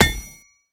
sfx_axe_hit_metal.mp3